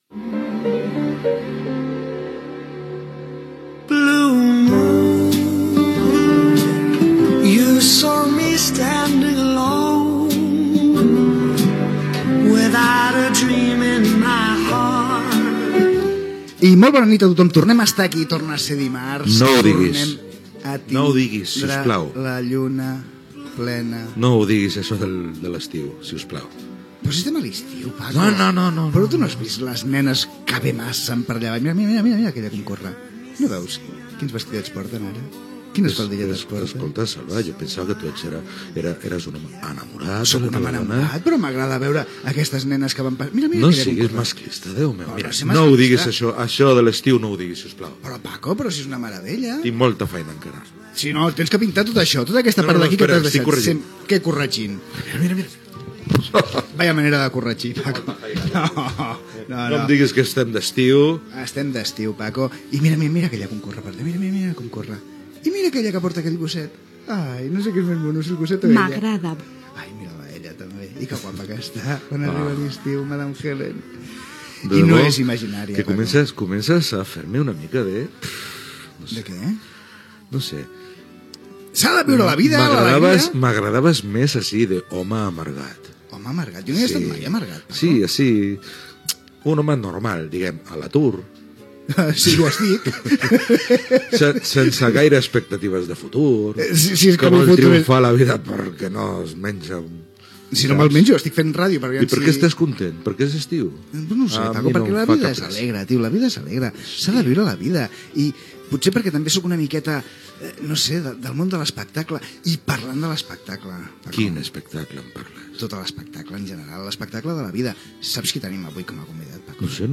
Careta i presentació del programa
FM